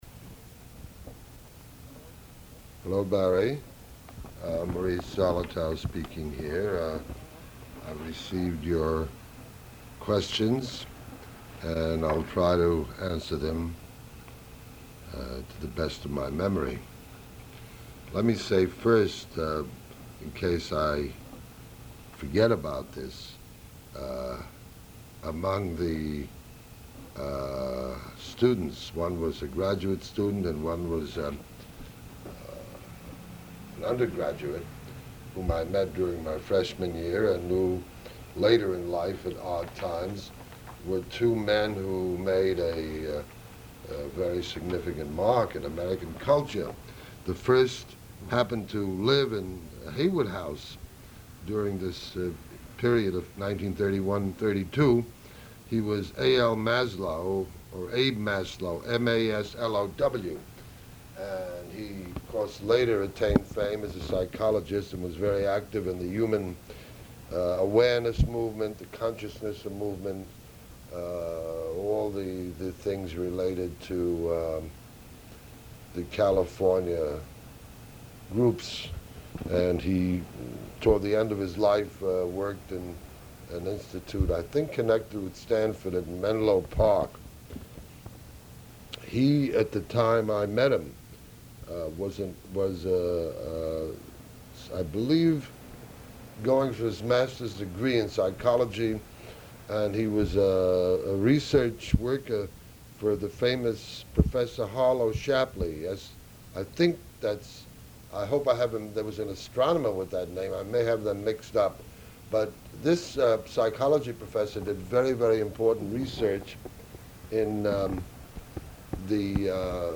Oral History Interview: Maurice Zolotow (0369)